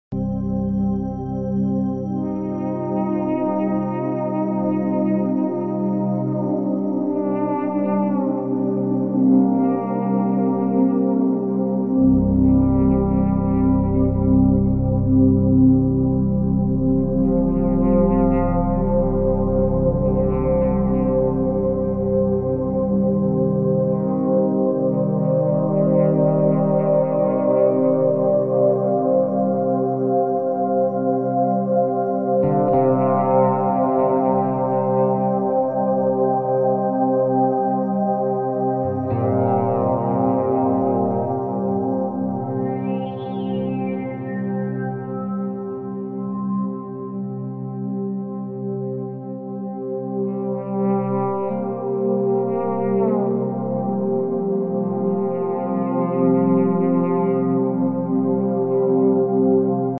🇧🇷Ondas Alfa 8hz para Relaxamento sound effects free download
🧘🏽 🇺🇸8 Hz Alpha Waves for Deep Relaxation and Mental Clarity.